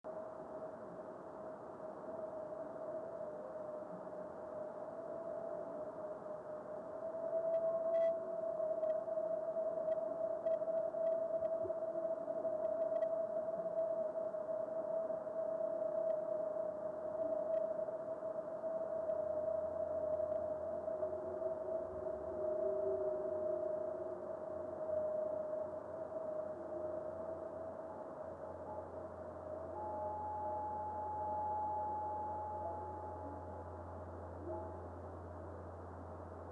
GTwo good visual fireballs.